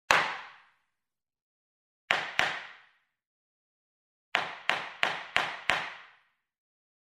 На этой странице собраны разнообразные звуки, связанные с судебными процессами: от характерных ударов молотка судьи до шума зала заседаний.
Звон молотка судьи